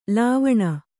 ♪ lāvaṇa